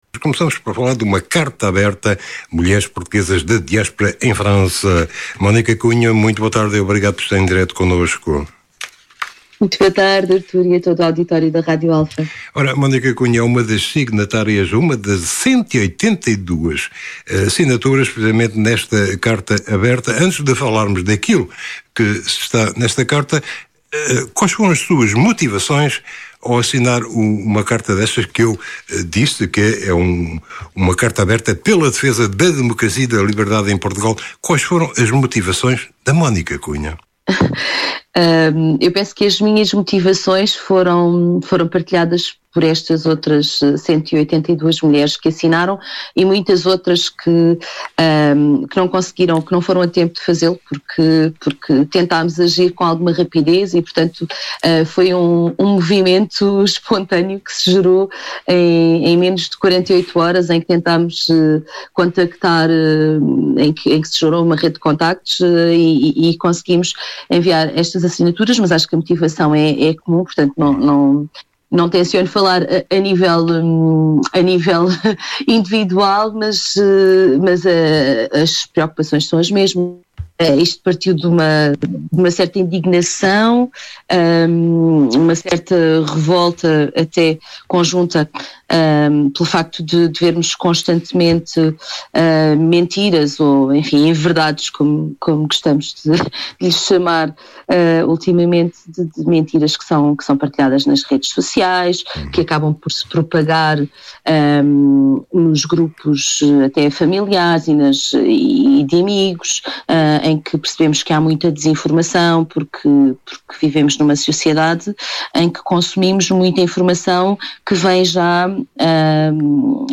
Em entrevista no programa Passagem de Nível